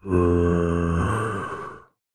zombie3.ogg